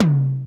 DMX TOM 6.wav